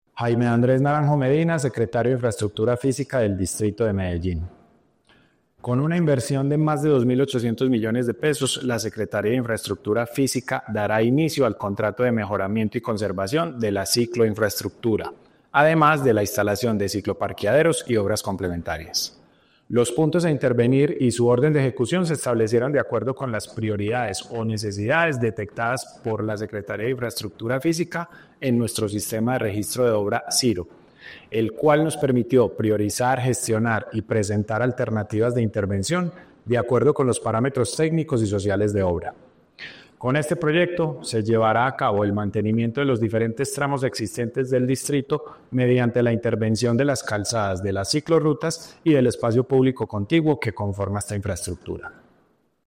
Palabras de Jaime Andrés Naranjo Medina, secretario de Infraestructura Física